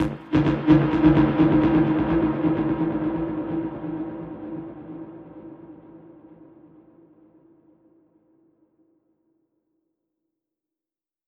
Index of /musicradar/dub-percussion-samples/85bpm
DPFX_PercHit_D_85-03.wav